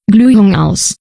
Diesen Logschen Schalter dann in Sprachausgabe verwendet um Sprachausgabe für Glühmeldung zu geben.